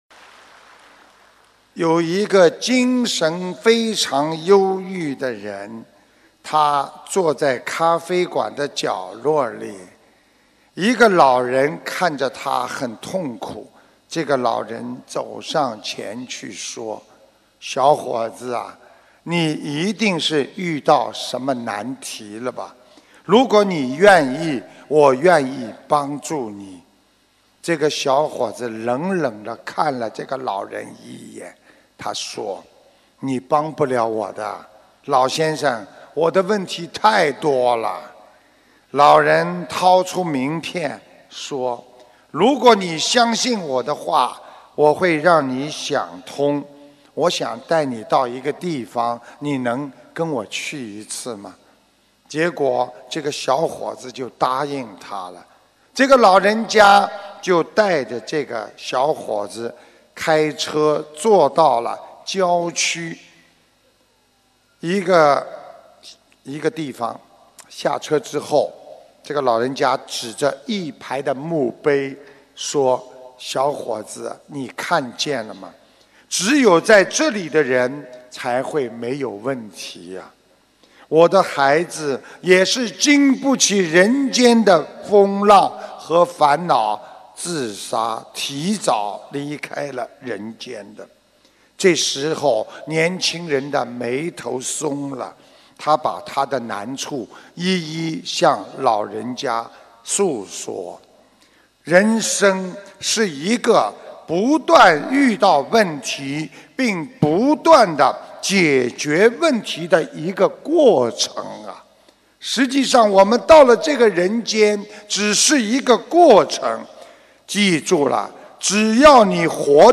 音频：忧郁小伙子·师父讲小故事大道理